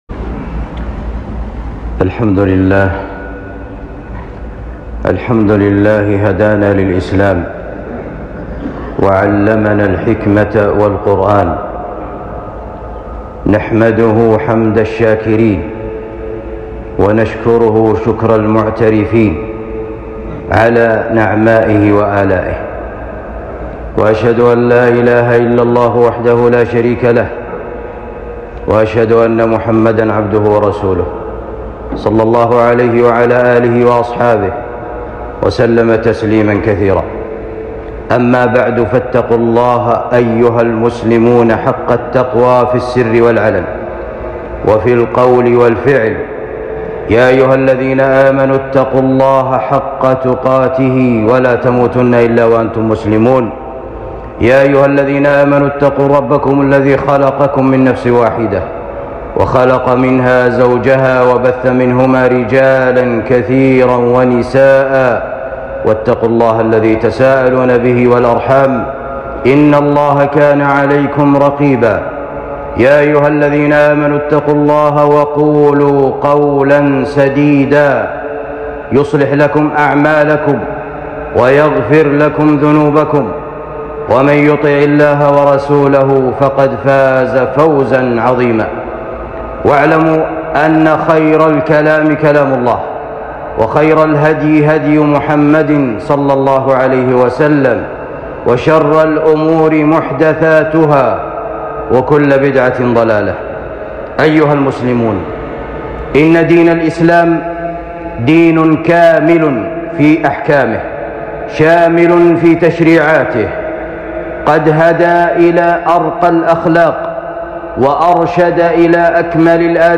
خطبة جمعة بعنوان آفات اللسان وخطر الكلام